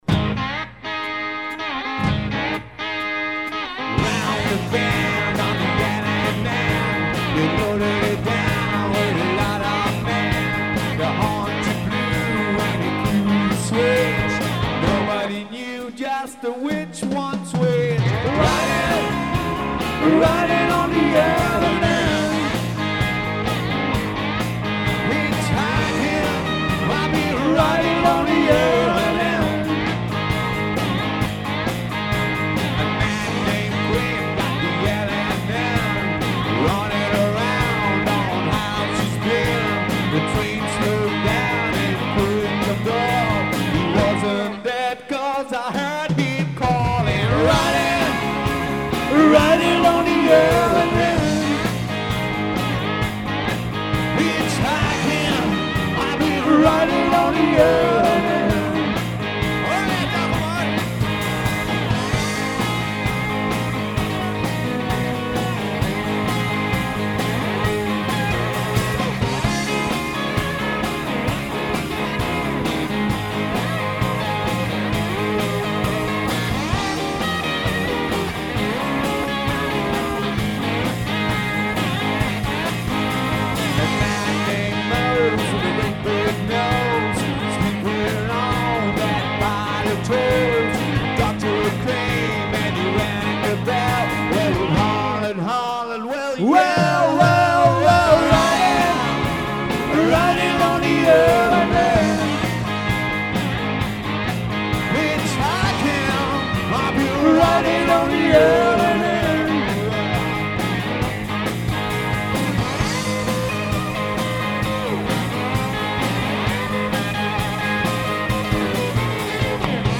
gitarre
Basgitarre
gesang
schlagzeug